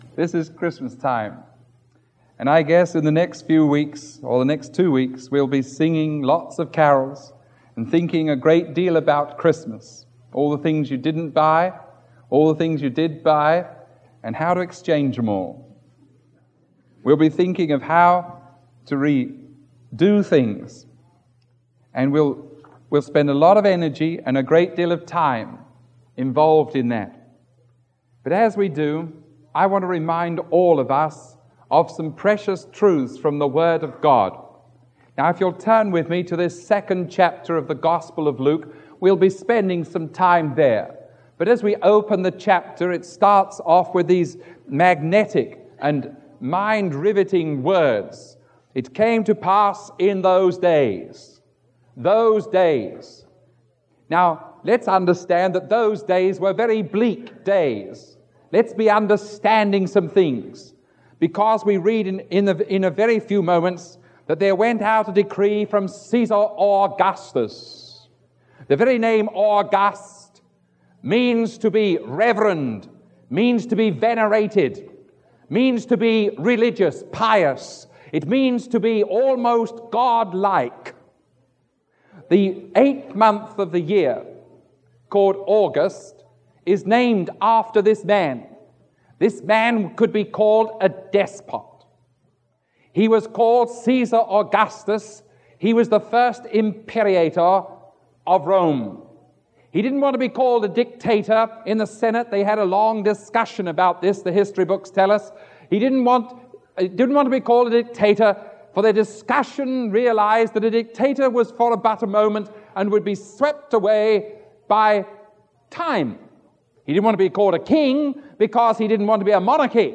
Christmas